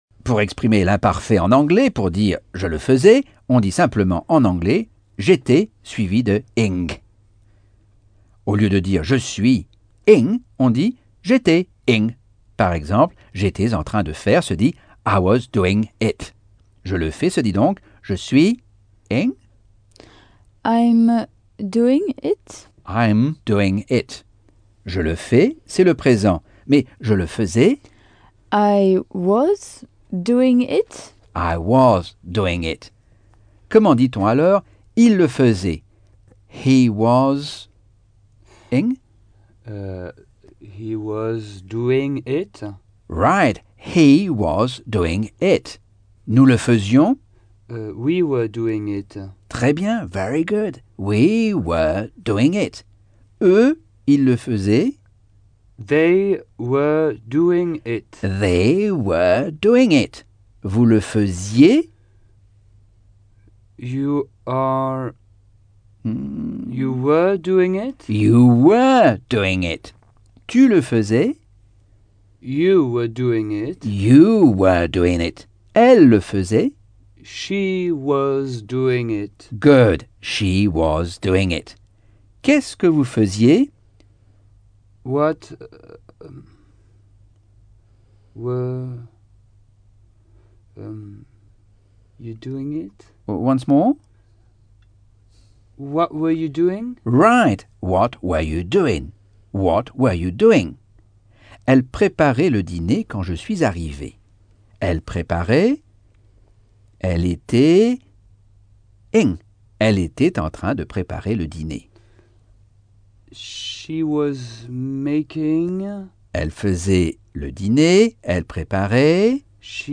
Leçon 6 - Cours audio Anglais par Michel Thomas - Chapitre 7